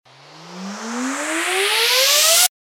FX-727-RISER
FX-727-RISER.mp3